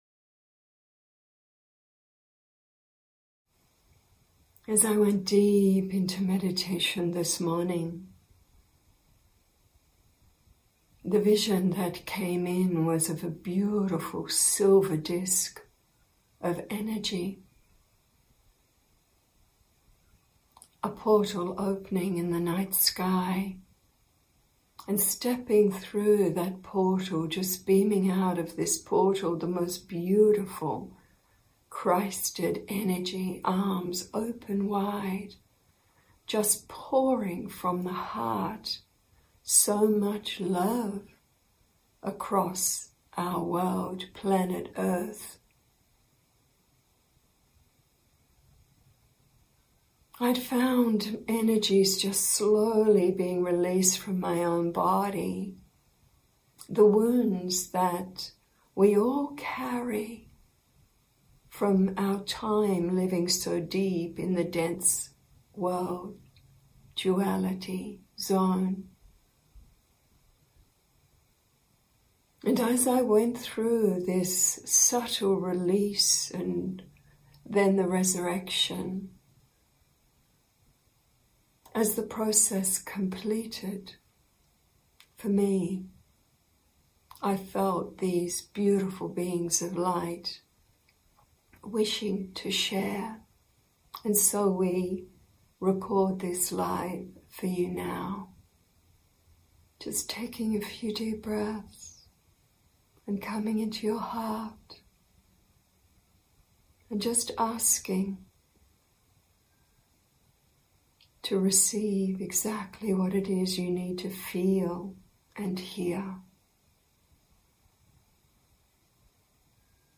April 11th – 2020 – After an intense but incredible meditation today our Light Being friends came in to share this live message that is so applicable to this Easter time and what is before us now on earth.